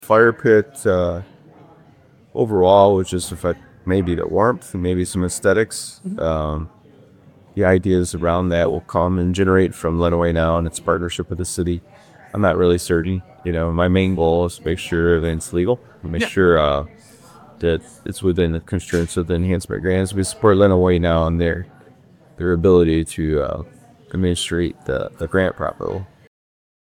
WLEN News spoke with Adrian City Manager Chad Baugh about the decision: